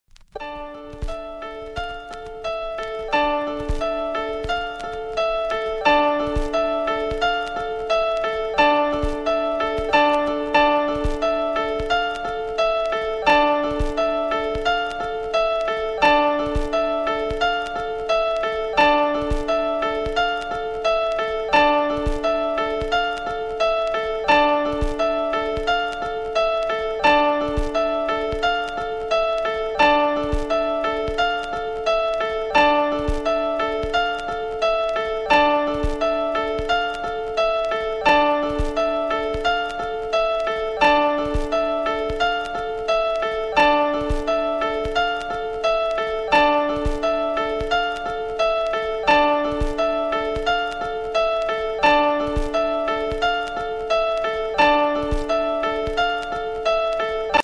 • Качество: 128, Stereo
саундтреки
спокойные
без слов
instrumental hip-hop